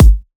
edm-kick-68.wav